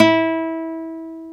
NYLON E 3.wav